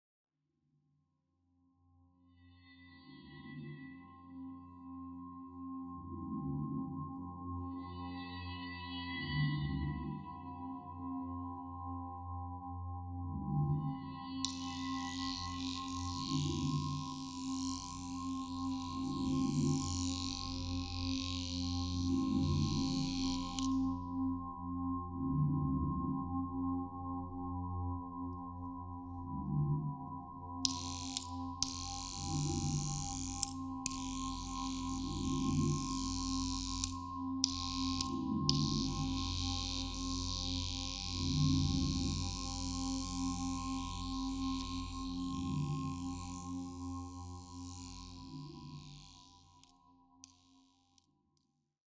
immersive audio-visual short film
centred on the tactile and sonic world of hair